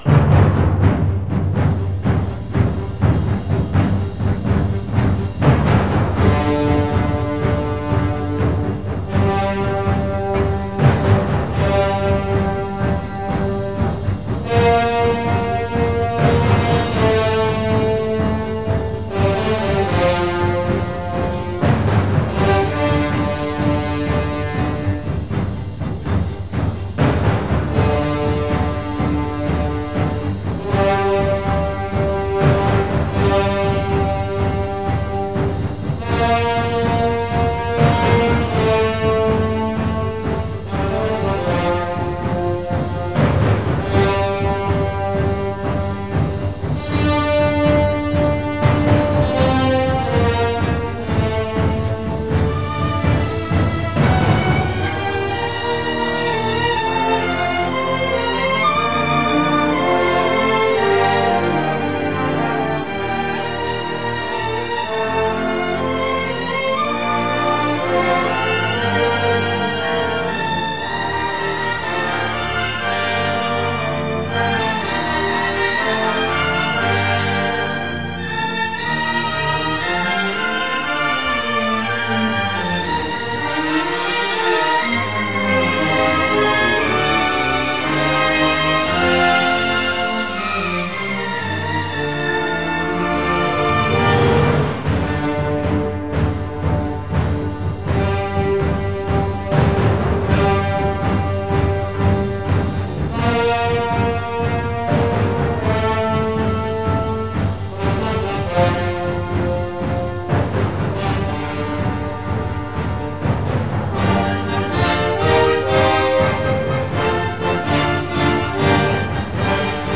Musica mediterranea di chiaro timbro 'rozsiano'
Di buona qualità.